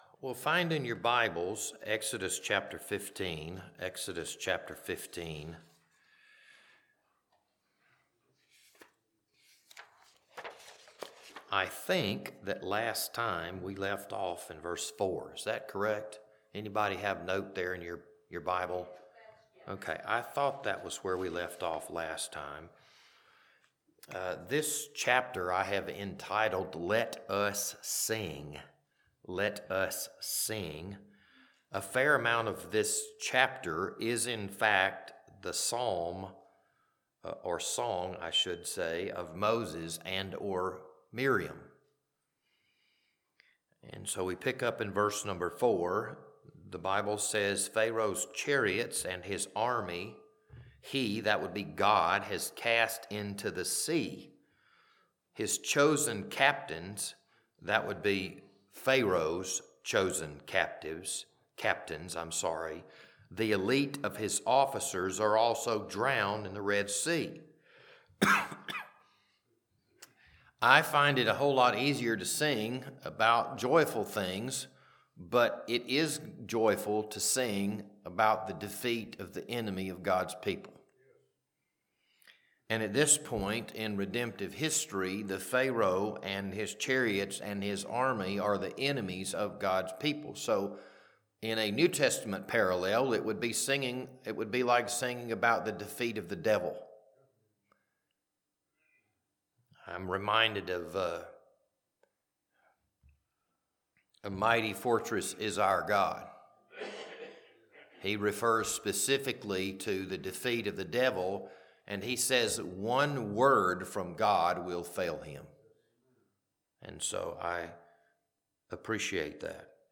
This Wednesday evening Bible study was recorded on January 14th, 2026.